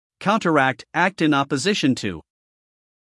英音/ ˌkaʊntərˈækt / 美音/ ˌkaʊntərˈækt /